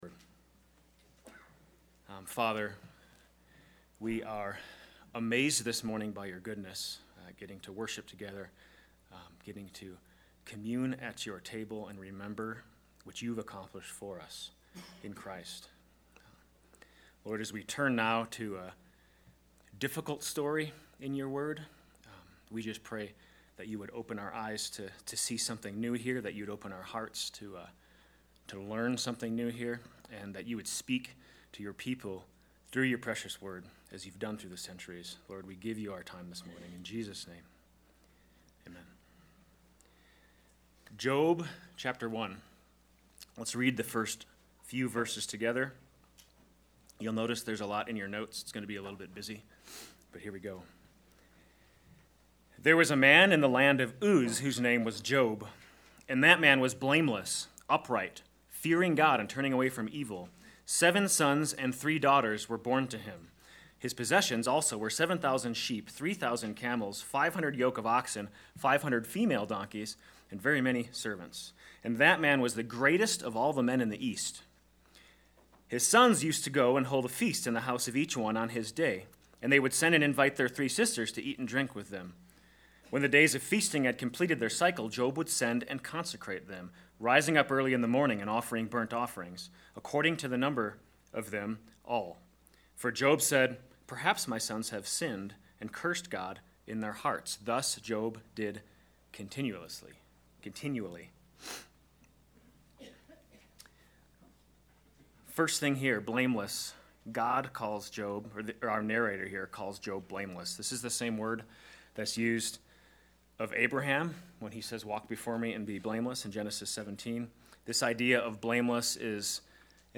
Sermons | Mountain Christian Church